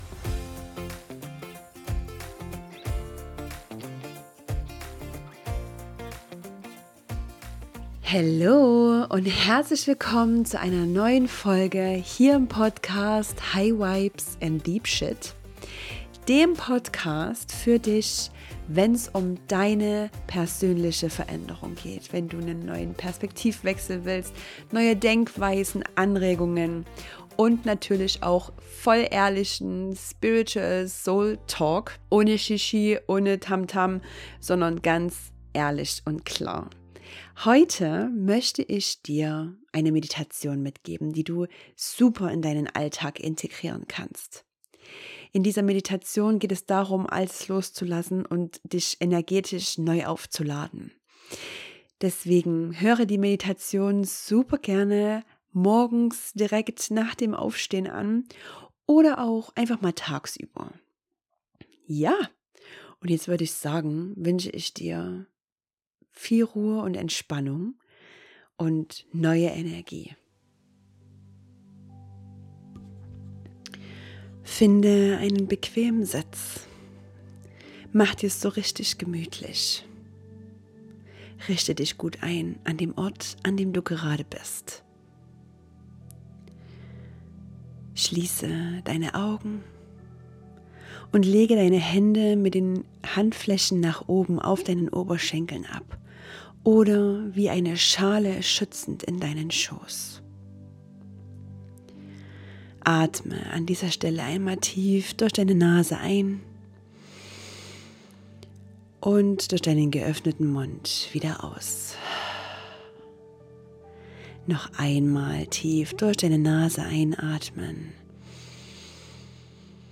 Diese Meditation ist dein kleiner Rückzugsort für zwischendurch.